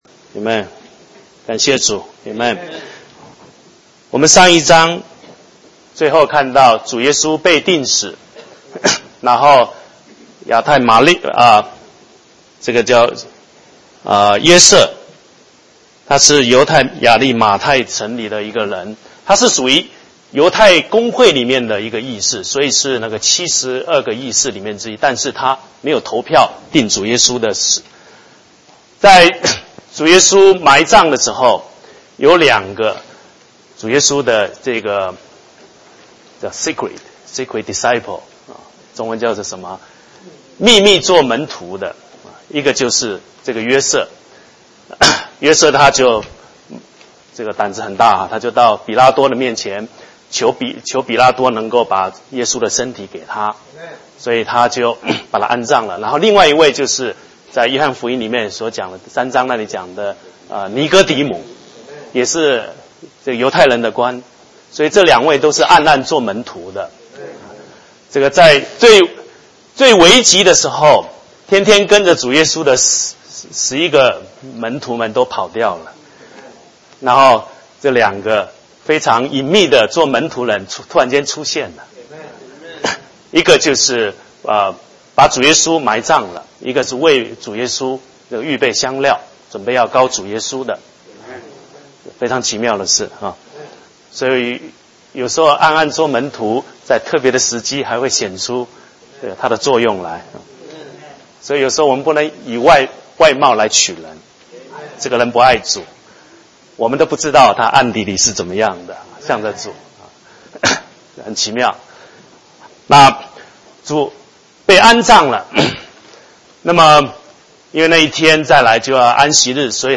主日聚會